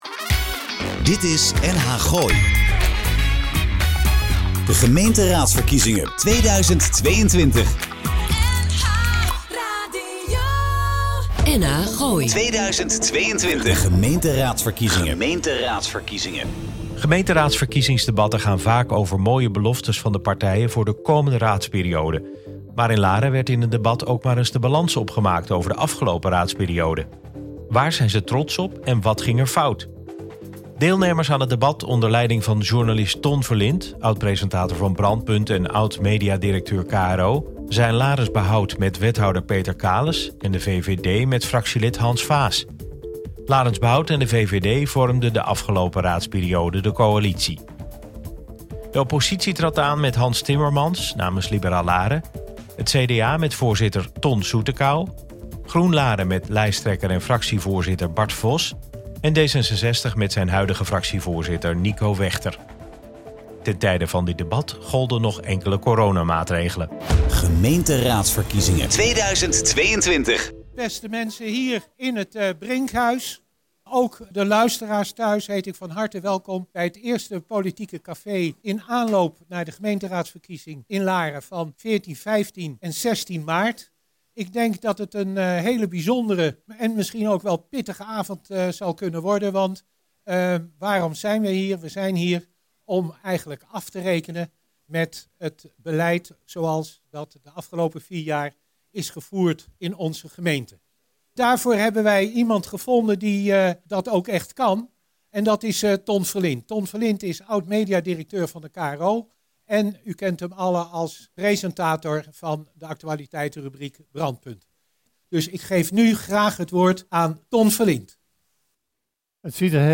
nh-gooi-verkiezingstijd-nh-gooi-verkiezingsdebat-laren.mp3